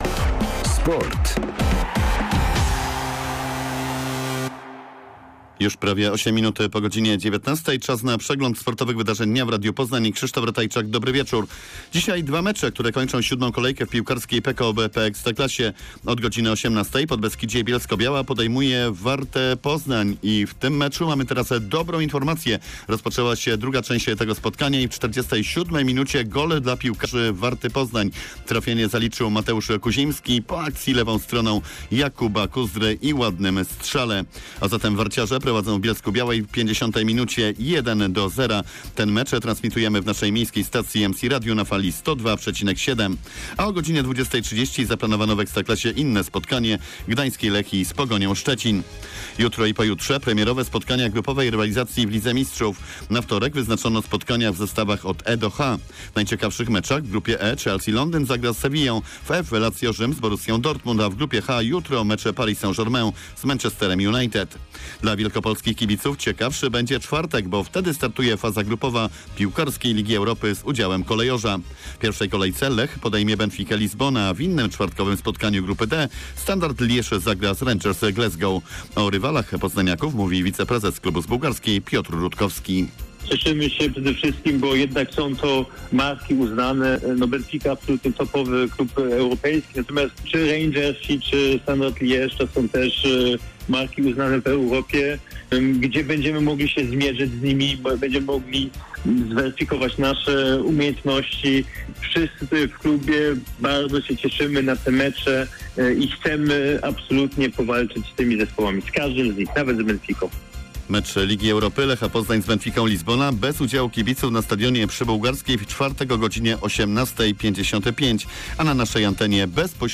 19.10. SERWIS SPORTOWY GODZ. 19:05